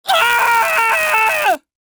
Screams Male 02
Screams Male 02.wav